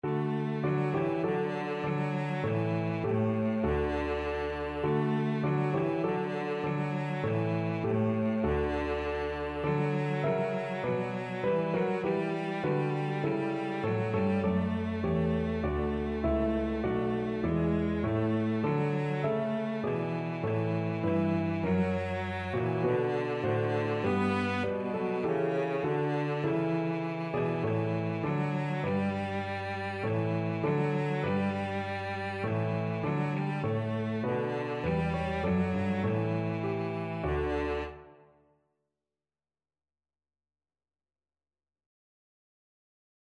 Cello
4/4 (View more 4/4 Music)
Moderato
D major (Sounding Pitch) (View more D major Music for Cello )